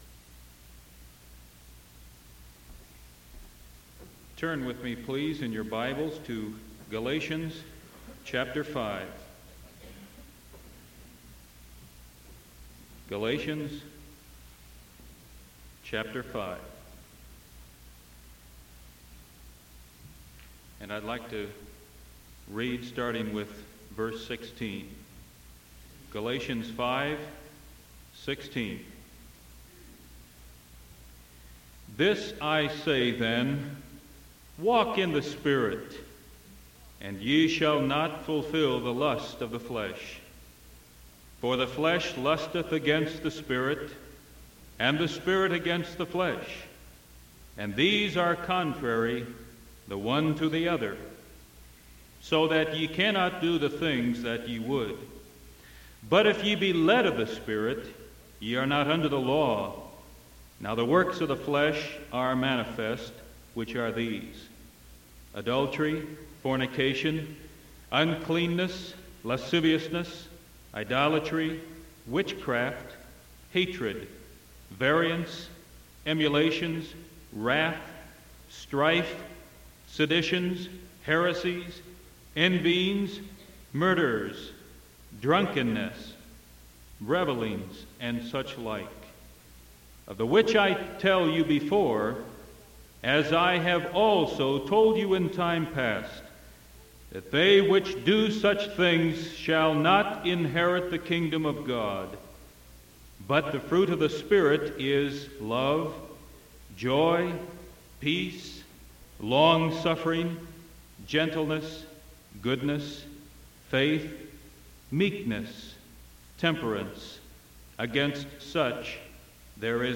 Sermon December 1st 1974 PM